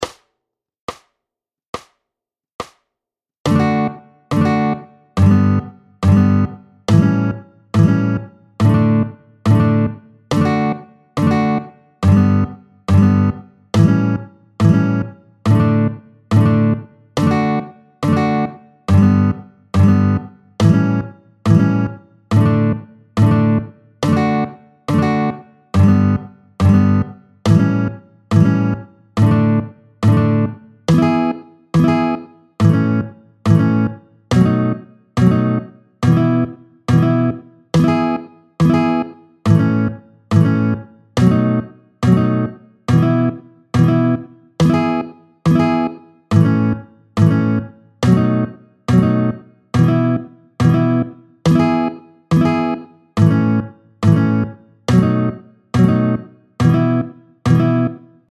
21-01 Ca commence à devenir un peu longuet, tempo 70